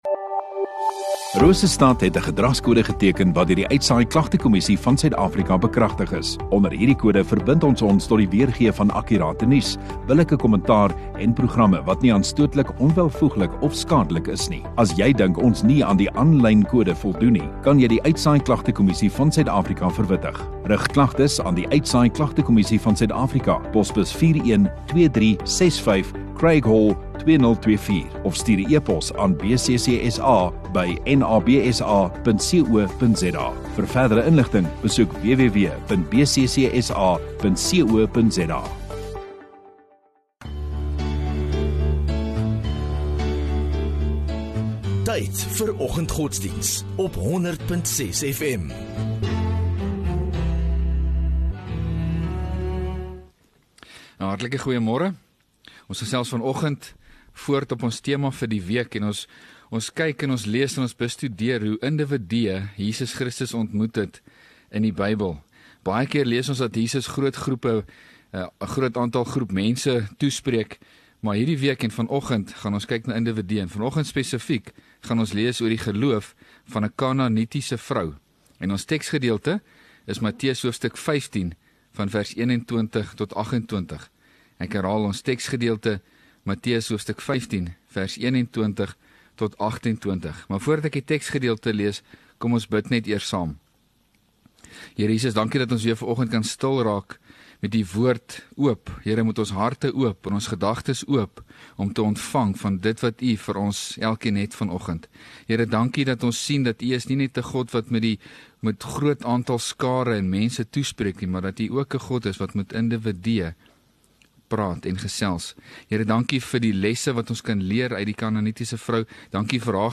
10 Sep Woensdag Oggenddiens